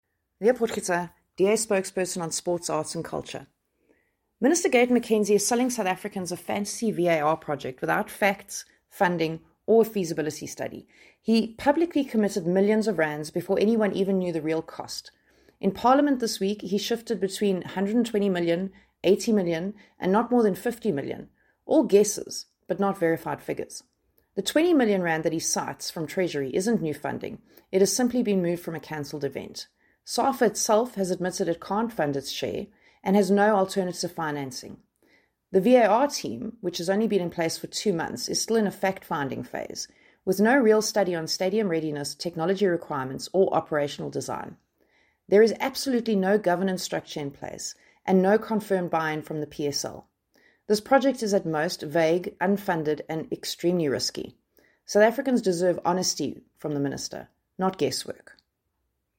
English soundbite by Leah Potgieter MP.